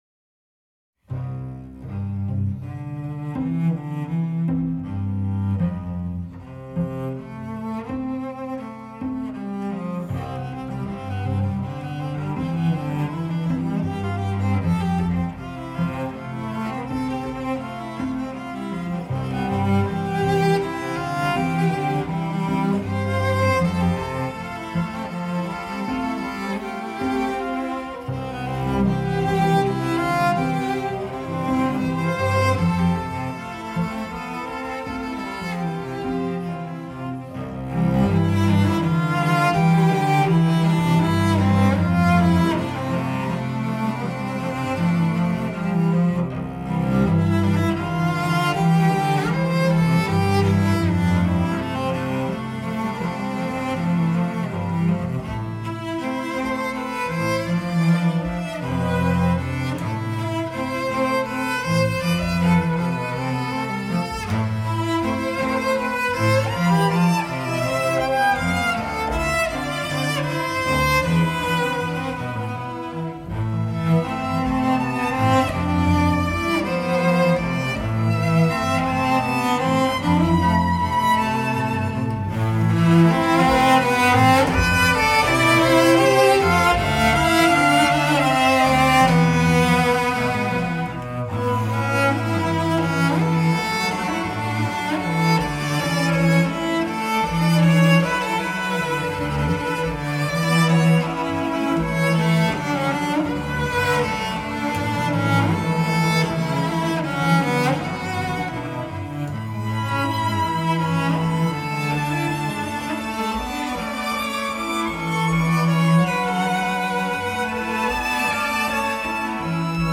·        Mood: light, serenely joyful
a very sweet, peaceful, lyrical and tonal piece.
CELLO ENSEMBLE
4 Cellos